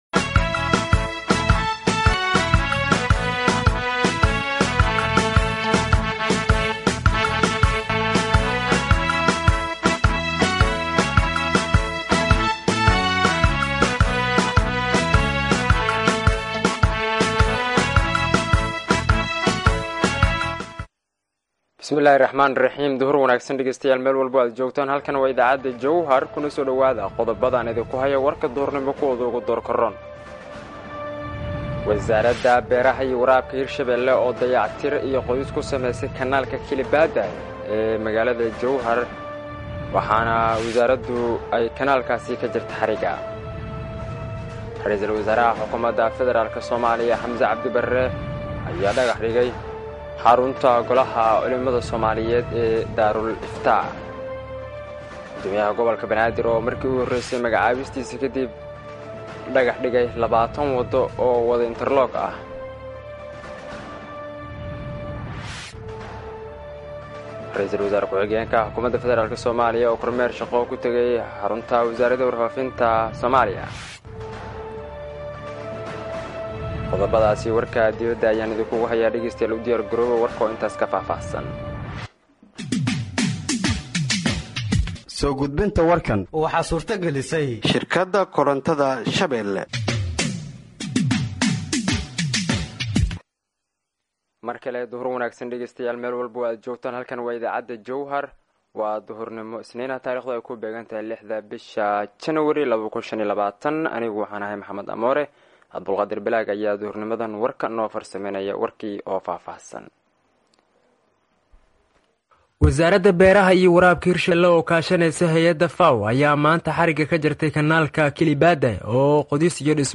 Dhageeyso Warka Duhurnimo ee Radiojowhar 06/01/2025